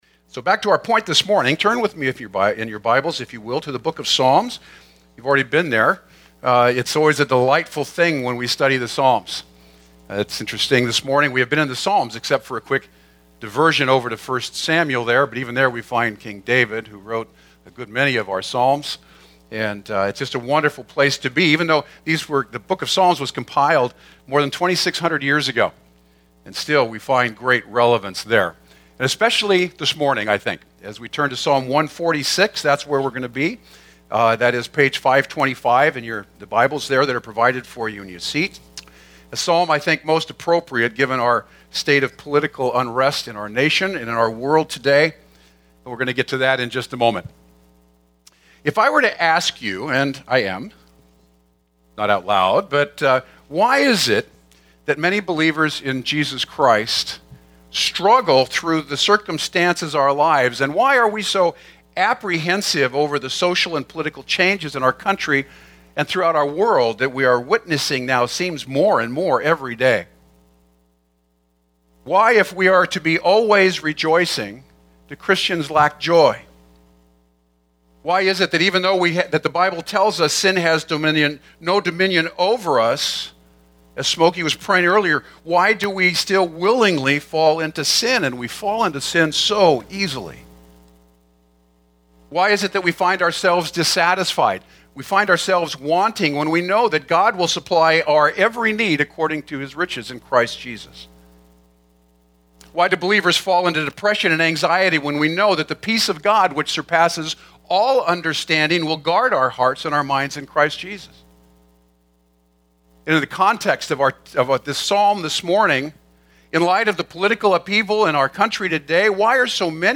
Put Not Your Trust in Princes Guest Preacher